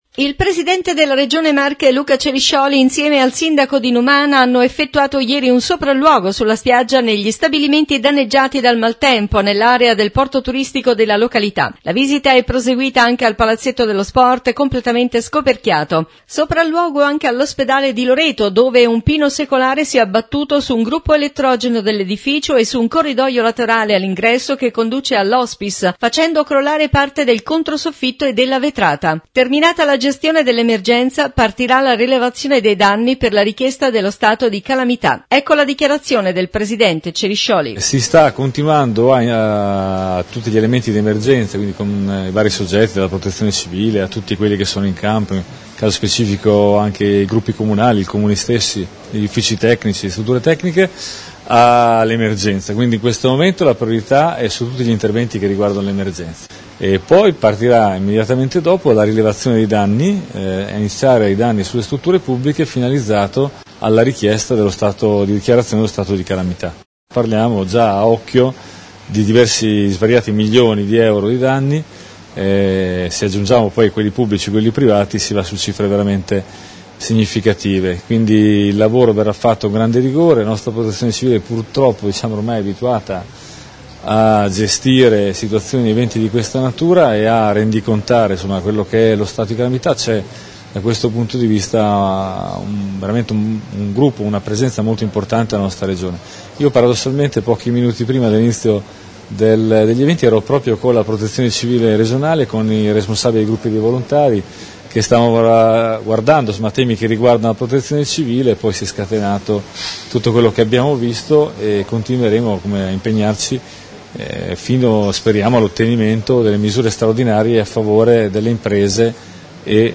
New Radio Star | Notizie Regione … Maltempo: il presidente Ceriscioli ha effettuato un sopralluogo a Numana (stabilimenti e palasport) e all’ospedale di Loreto dove è caduto un albero secolare all’interno della struttura Intervista Luca Ceriscioli – Presidente Regione Marche